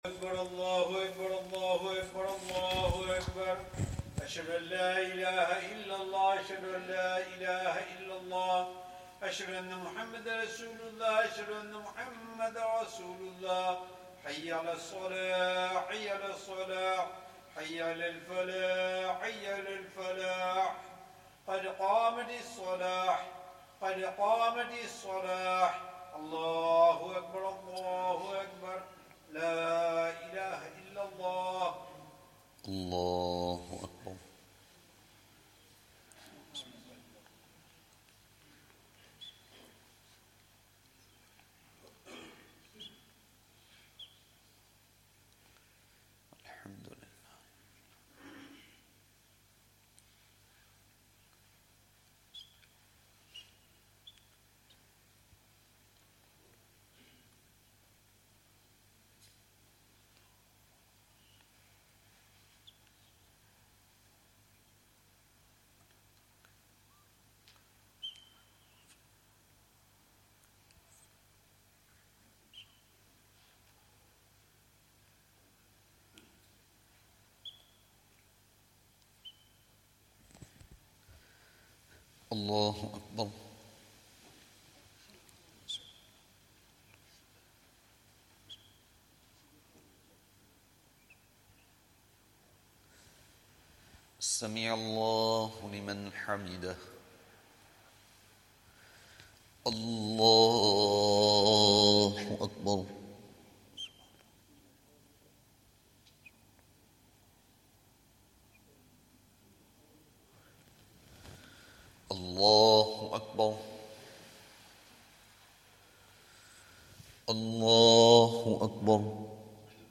Zakariyya Jaam'e Masjid, Bolton